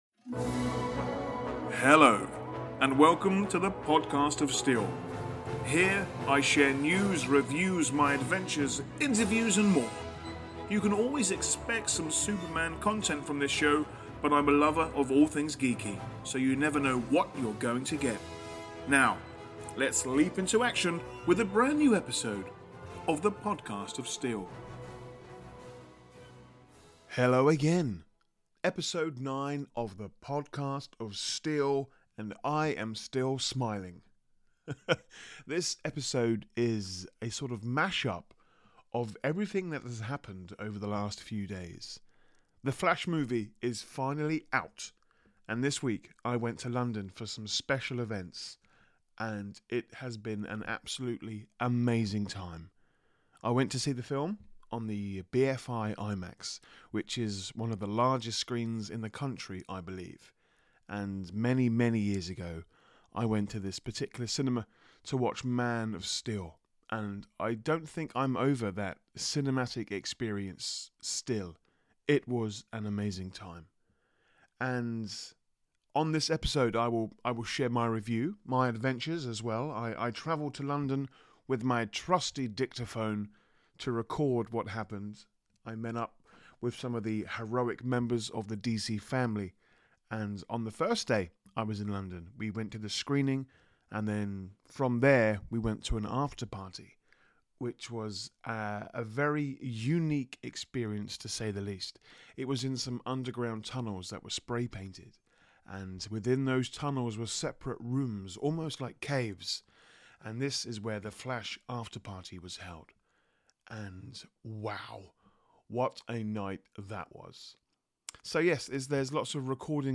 This episode was recorded in London and there are reviews, chats, updates, opinions and a meal! Recently I was invited there by Warner Bros UK to attend some exciting events and celebrate the release of The Flash movie.